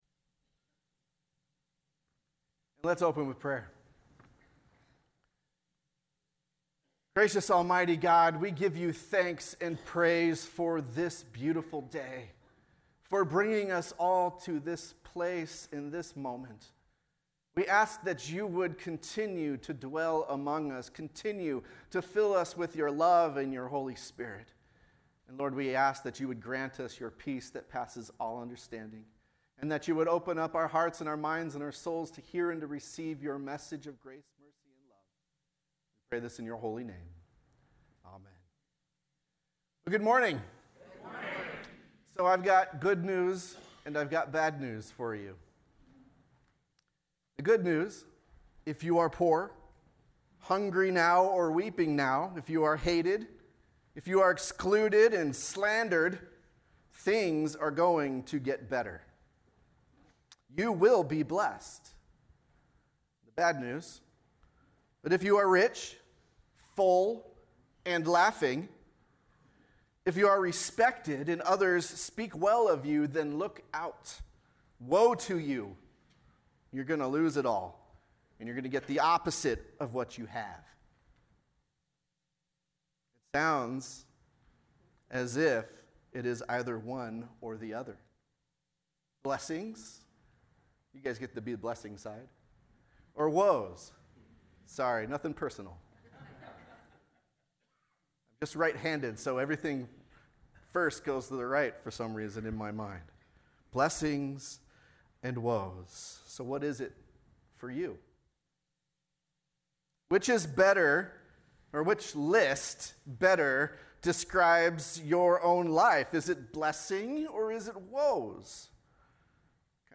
Homily - Sermon 11-3-19 | Kihei Lutheran Church